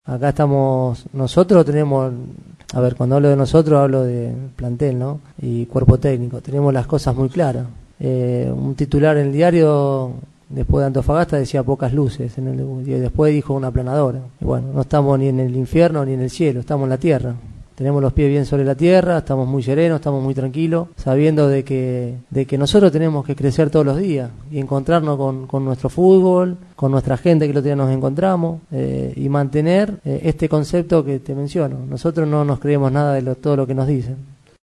Así reaccionó el adiestrador tras ese partido.
UCH-Beccacece-Ni-en-el-infierno-ni-en-el-cielo.mp3